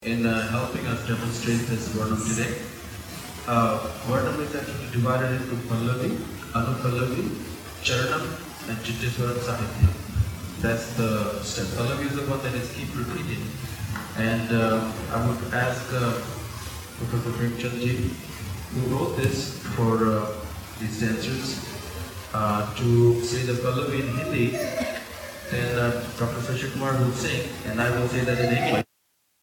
Here's the continous noise filter. It sounds better than the audacity one.
dc6-continuous_noise_reduction.mp3